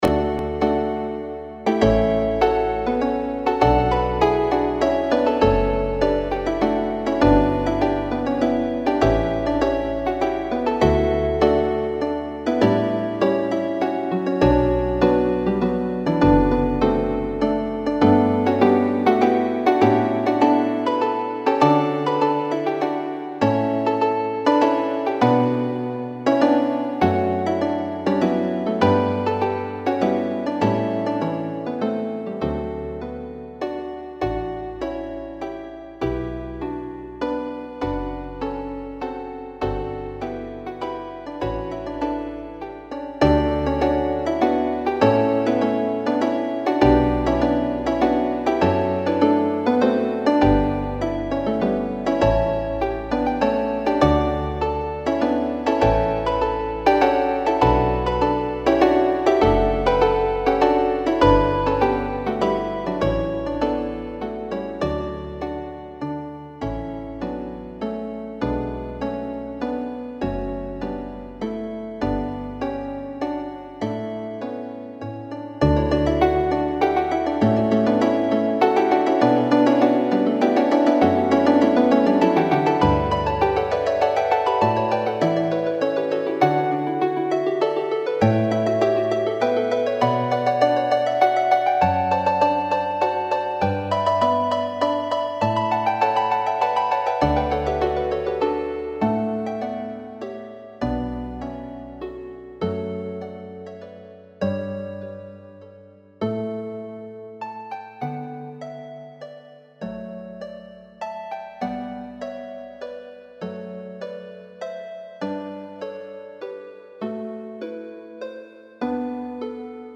is for four pedal harps.